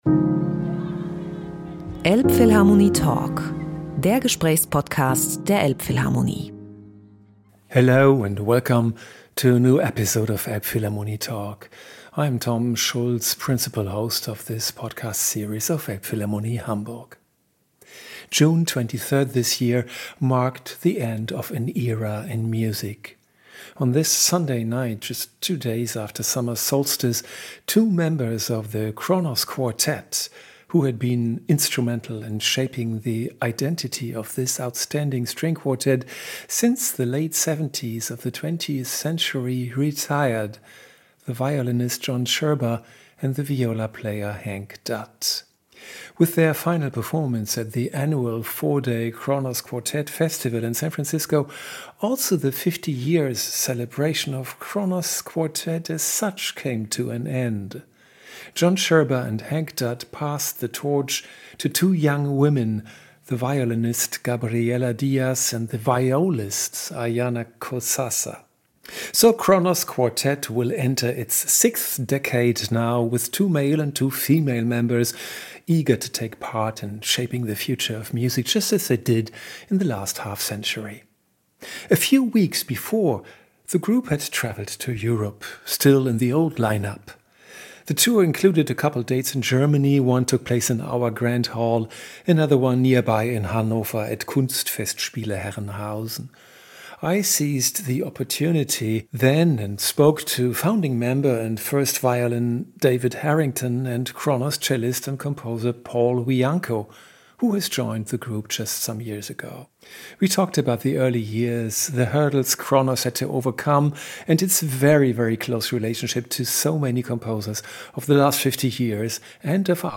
Elbphilharmonie Talk mit dem Kronos Quartet
Im »Elbphilharmonie Talk« berichten David Harrington und Paul Wiancko über die alltägliche Arbeit dieses weltberühmten Ensembles und wie es nach dem Weggang von John Sherba und Hank Dutt weitergehen wird, zwei Musikern, die nur wenige Jahre nach Gründung des Ensembles zum Kronos Quartet stießen und die sich Ende Juni 2024 in den Ruhestand verabschiedet haben.